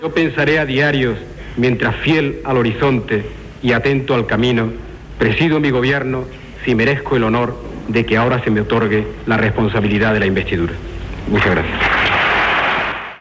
Paraules finals del discurs d'investidura com a president del Govern de Felipe González.
Informatiu